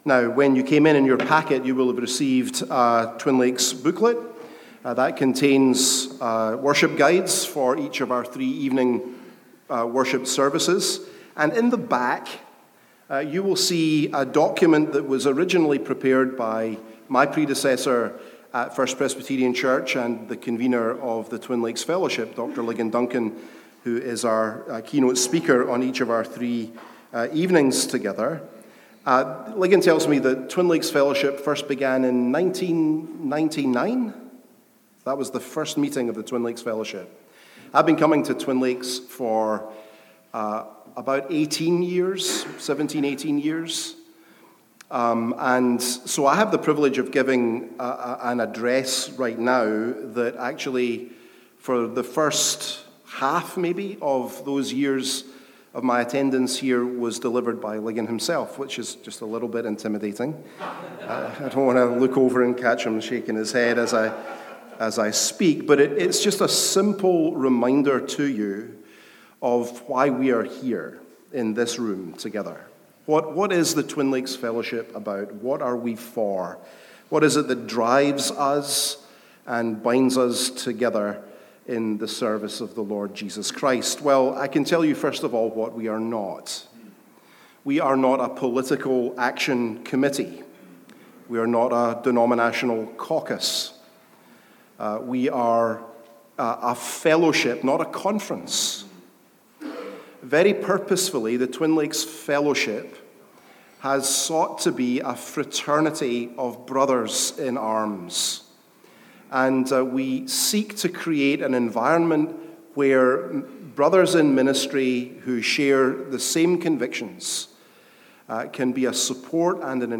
The Pastoral Epistles: TLF Welcome Lecture